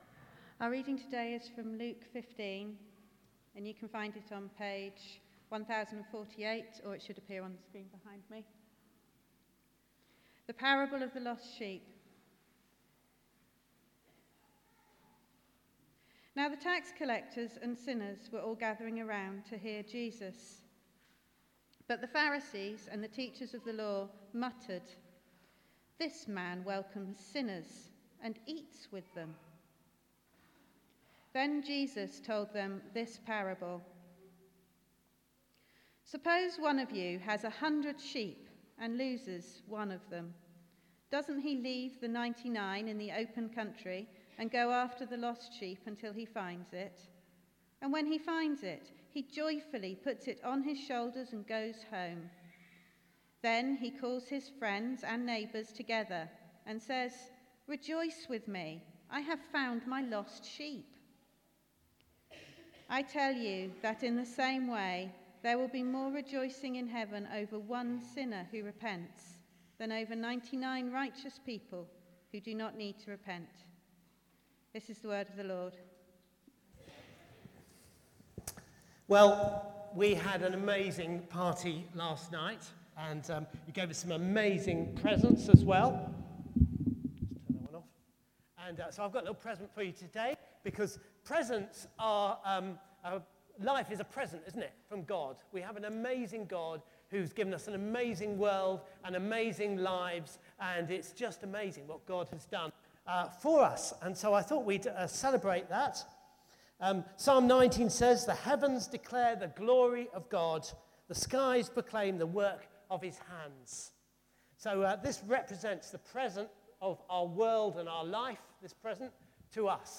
Bible Text: Luke 15:1-7 | Preacher